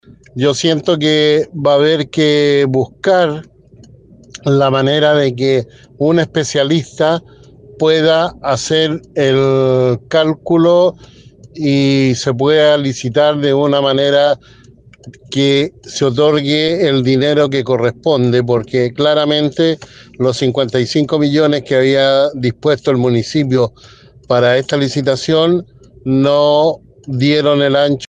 El concejal y presidente de la comisión de Patrimonio de Valparaíso, Dante Iturrieta, afirmó que este es un tema complejo y que hubo “errores de cálculo”, razón por la que no hubo empresa especializada que se haya interesado en la licitación.